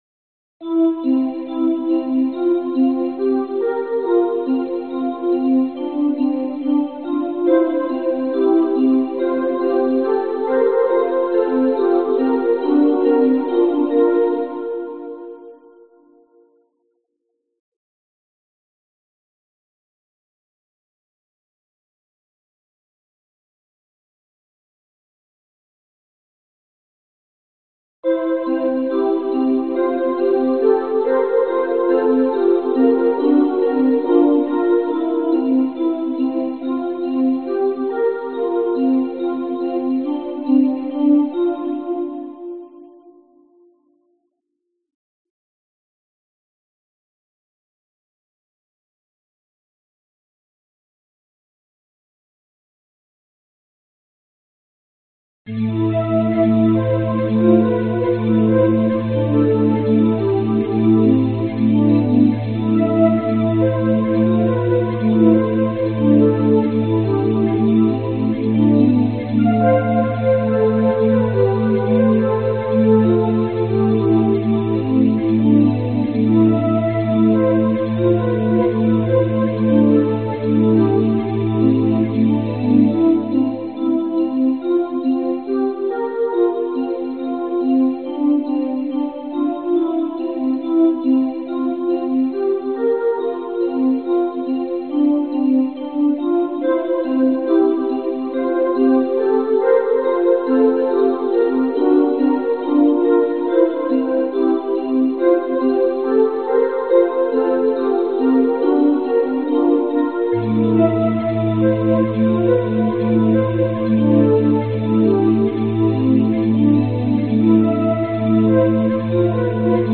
玻璃声
描述：一个玻璃声音的旋律。
Tag: fmc12 玻璃 器乐 循环 合成器